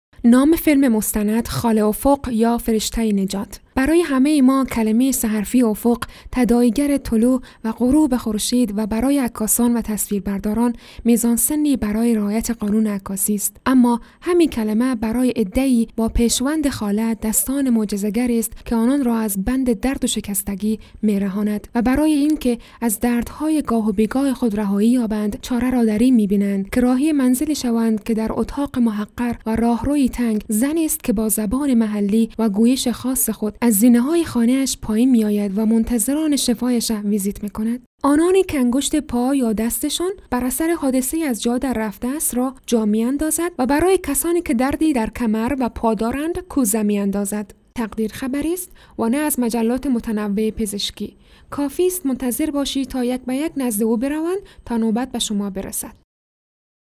Female
Adult